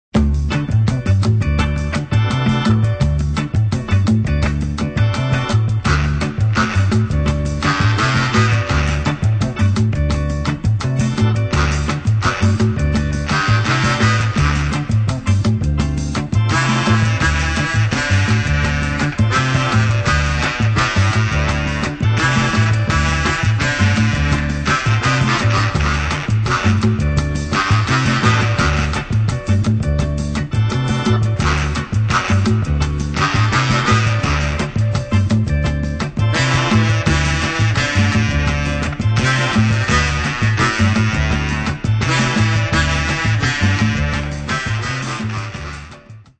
exciting fast instr.